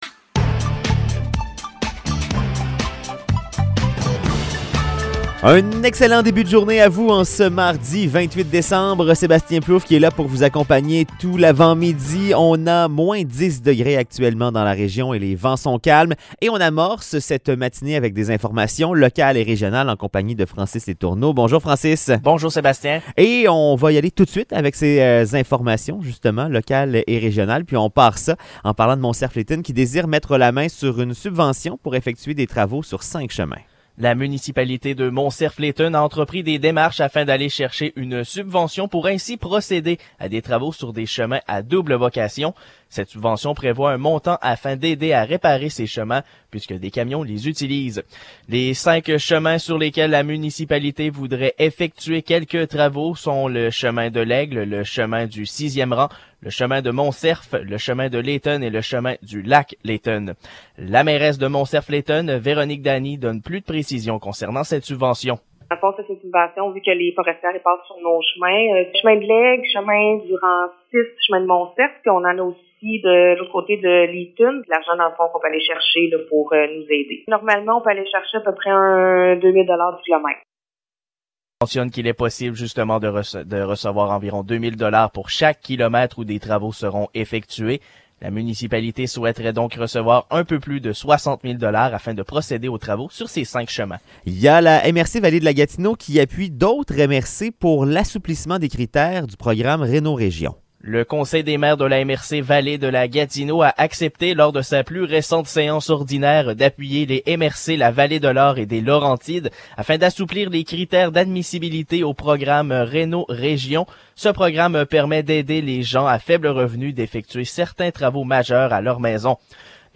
Nouvelles locales - 28 décembre 2021 - 7 h